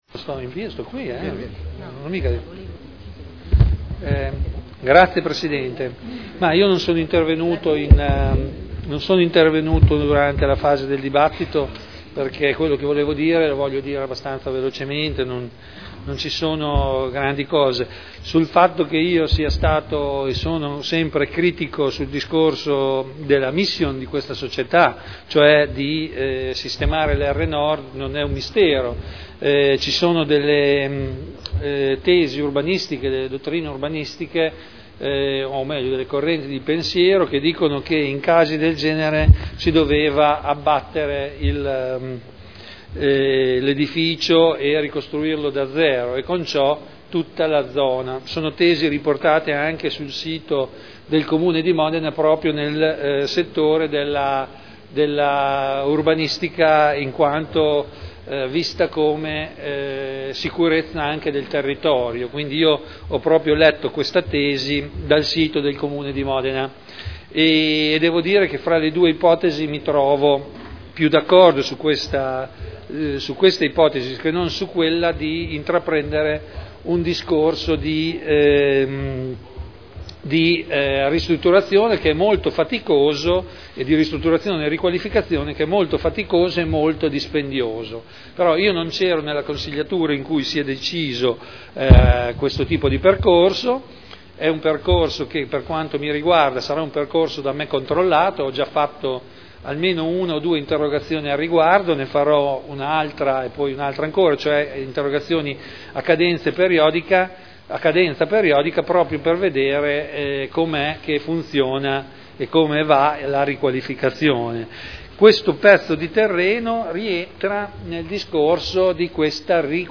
Seduta del 22/12/2011. Dichiarazione di voto su proposta di deliberazione.